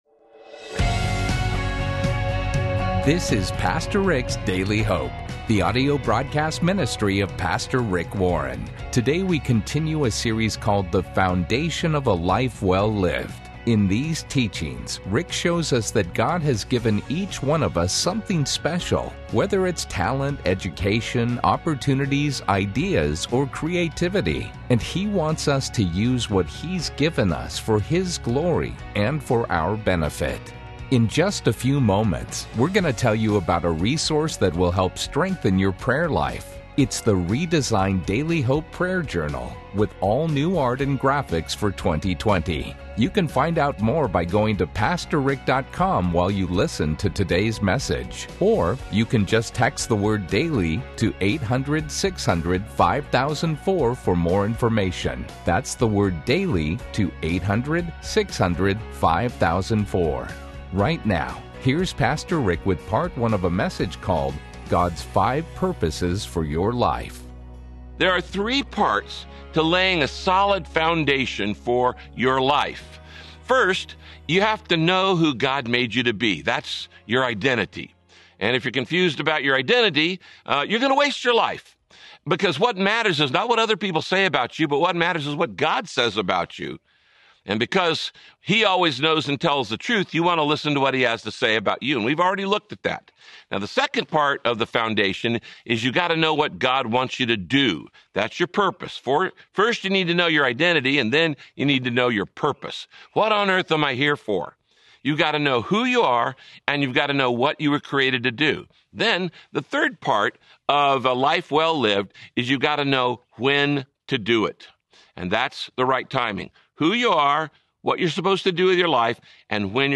Pastor Rick teaches about the five purposes of your life: Be centered on God, learn to love others, grow in spiritual maturity, give something back, and tell others about God’s love.
Radio Broadcast